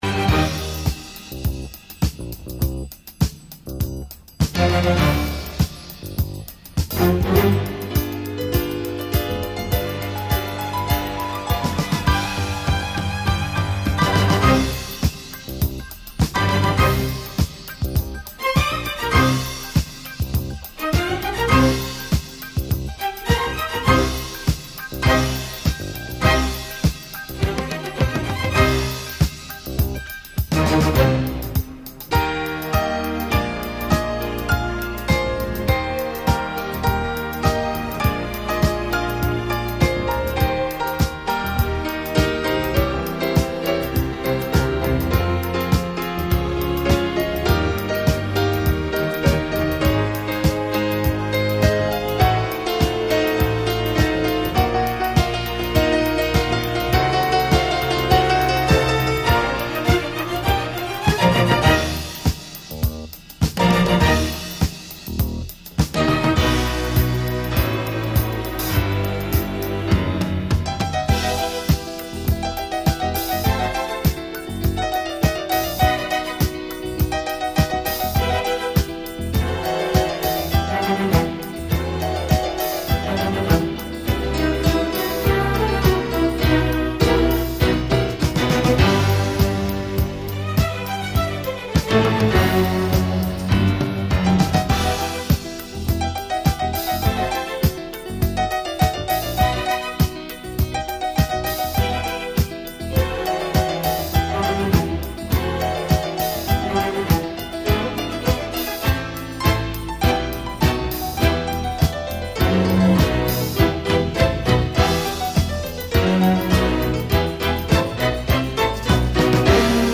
0223-钢琴名曲命运交响曲.mp3